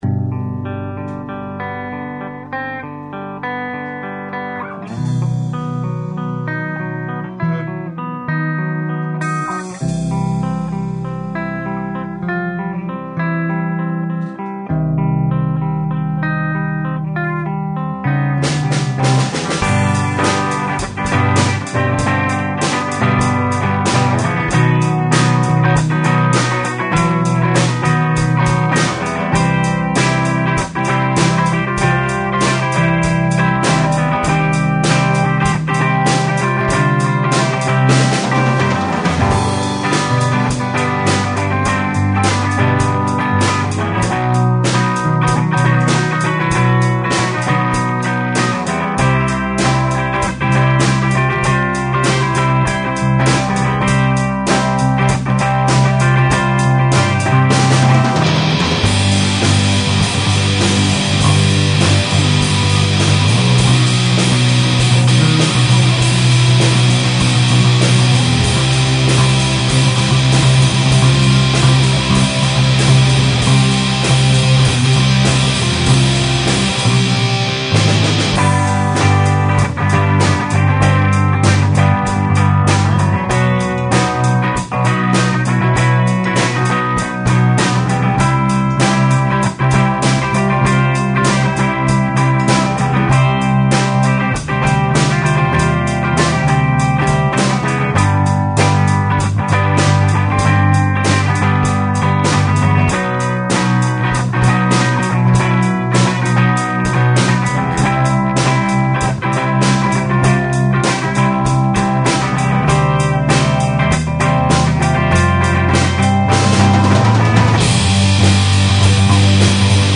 music only